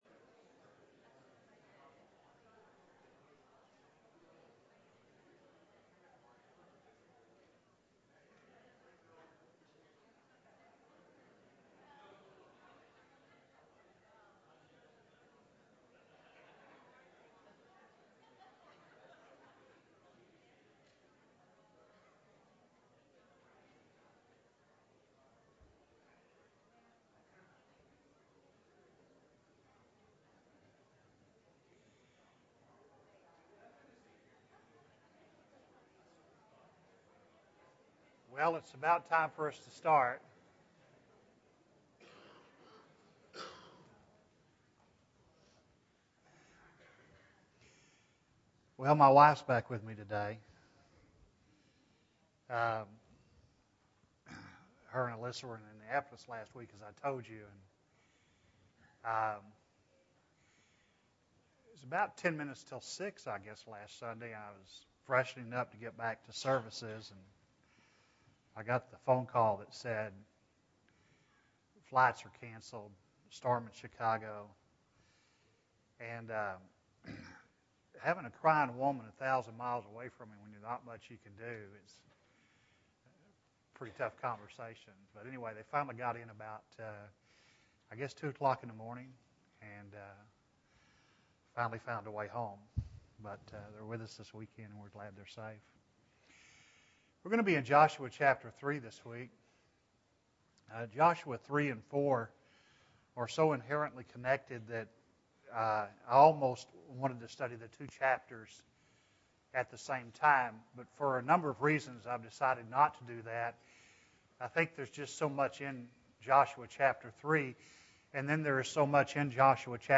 Joshua 3 (3 of 14) – Bible Lesson Recording
Sunday AM Bible Class